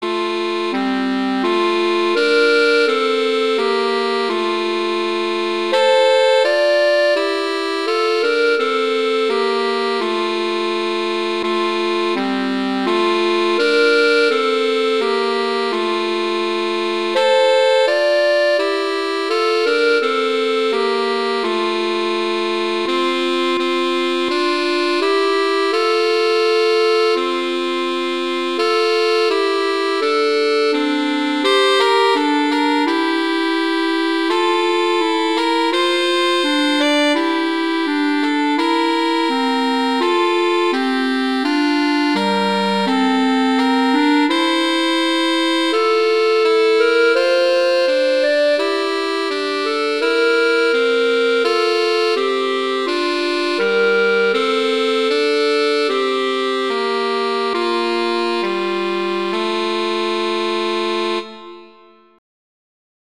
winter, holiday, hanukkah, hymn, sacred, children